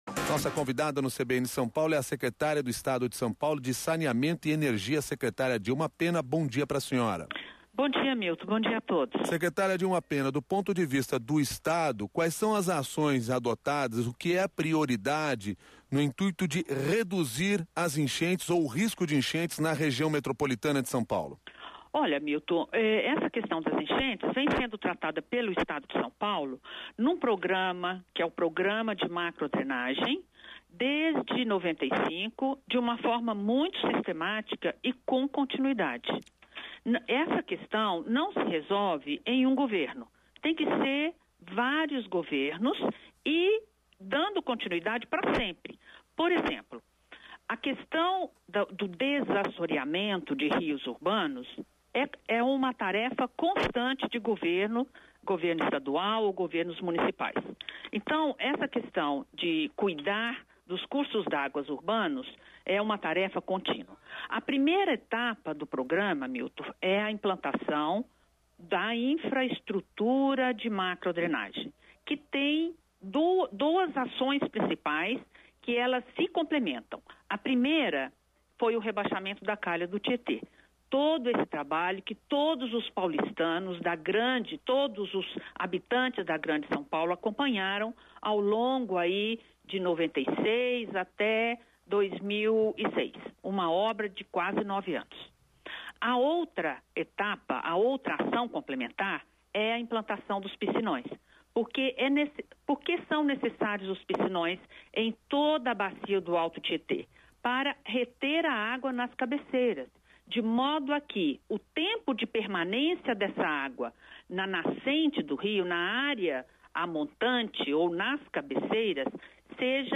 Ouça a entrevista da secretária Dilma Pena ao CBN SP